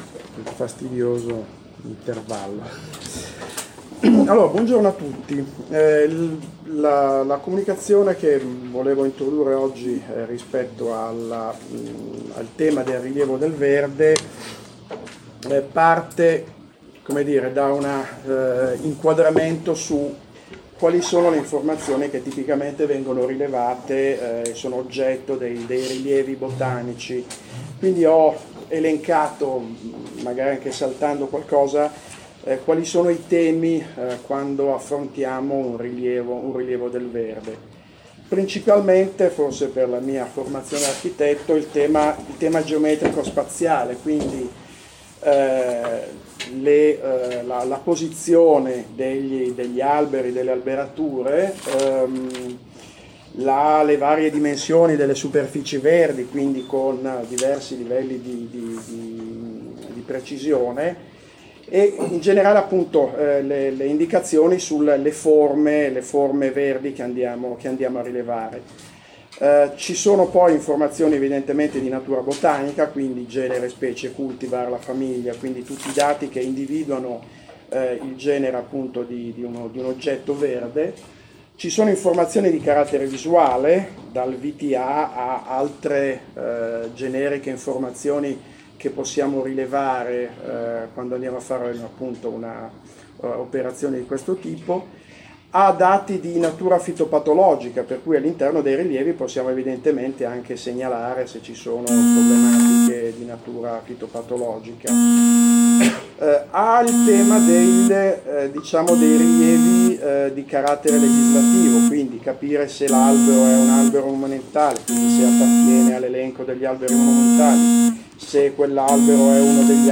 Brescia, 30 marzo 2017 – presso sede ODAF Brescia
Interventi al seminario